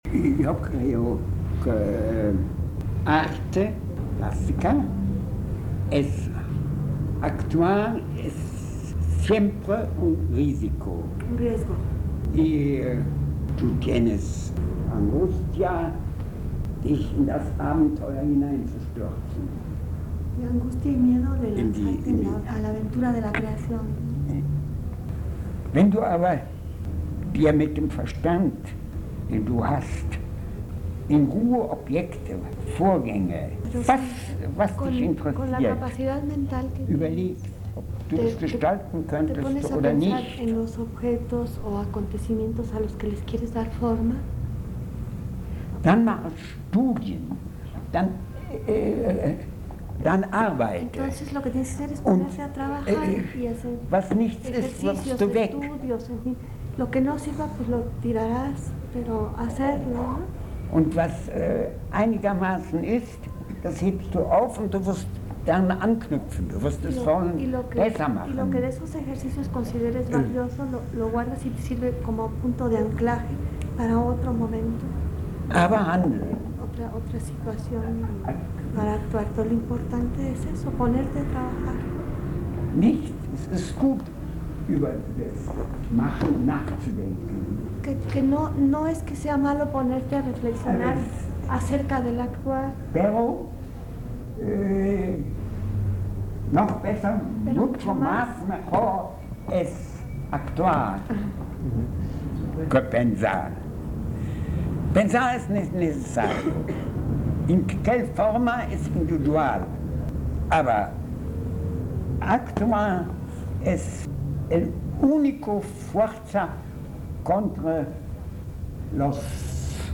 Las grabaciones se realizaron en el taller de pintura con jóvenes del barrio de Tepito, en la Casa de Cultura Enrique Ramírez y Ramírez, en el año de 1984.